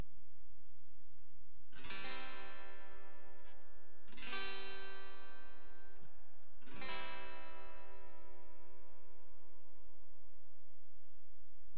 いつもの様にPCのマイク端子から録音
１弦ブリッジそば
出力が超小さい？
ノイズにまみれてお話になってません
直接PCに録音した音